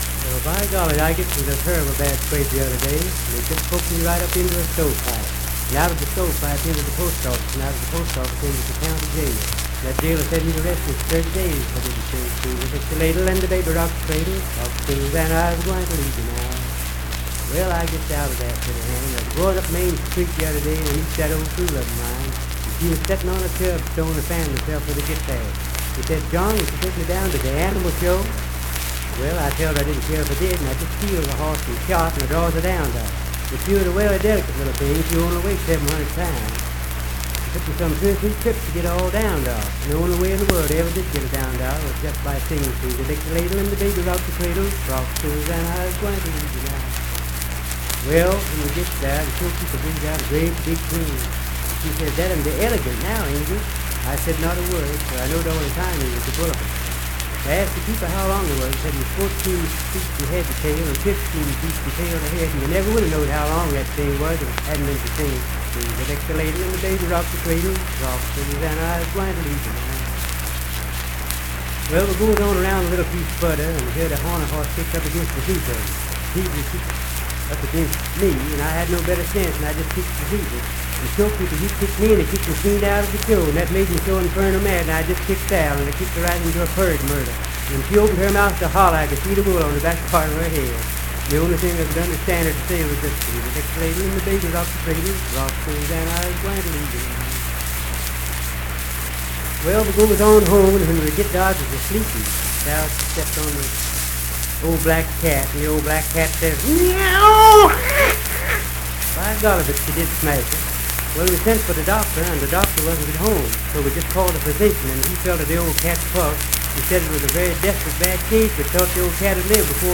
Unaccompanied vocal music
Verse-refrain 7(4)&R(4).
Folklore--Non Musical, Humor and Nonsense, Dance, Game, and Party Songs
Voice (sung)
Richwood (W. Va.), Nicholas County (W. Va.)